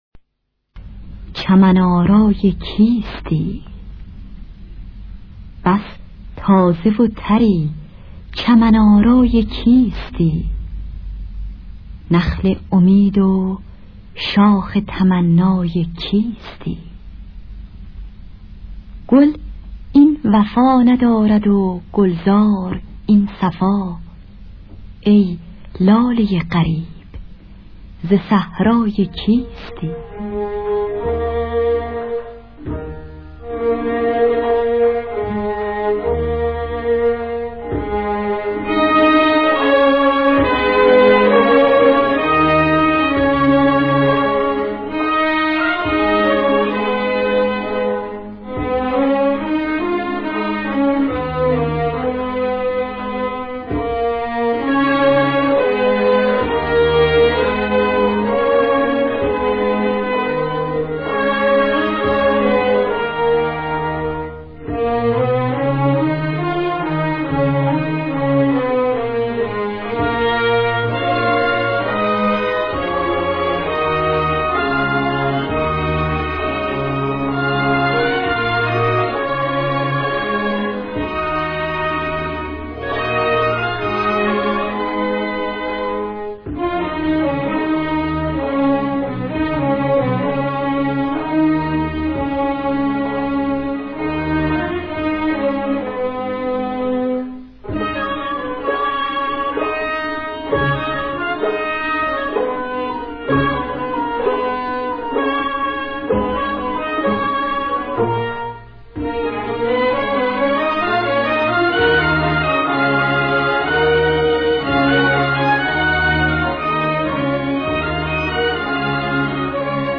در دستگاه ابوعطا